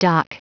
Prononciation du mot dock en anglais (fichier audio)
Prononciation du mot : dock